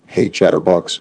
synthetic-wakewords / hey_chatterbox /ovos-tts-plugin-deepponies_Barack Obama_en.wav
ovos-tts-plugin-deepponies_Barack Obama_en.wav